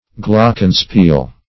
Glockenspiel \Glock"en*spiel`\, n. [G.; glocke bell + spiel